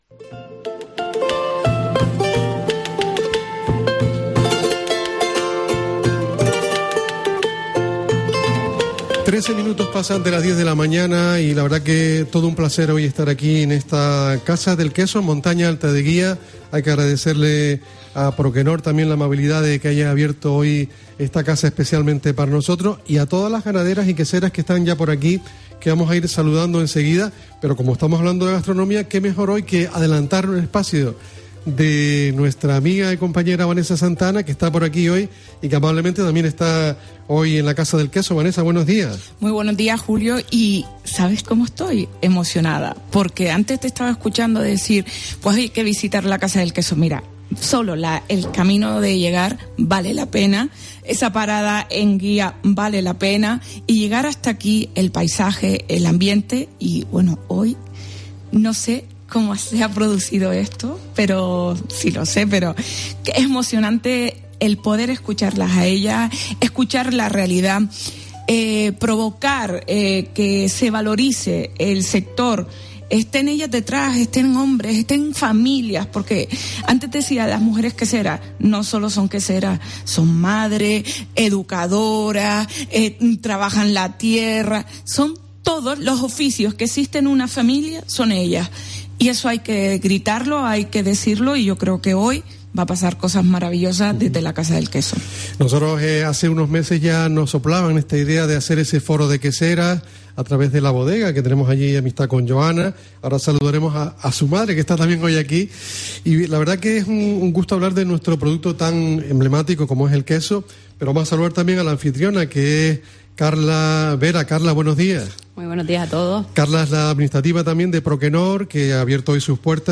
Herrera en COPE Gran Canaria, desde la Casa del Queso de Gran Canaria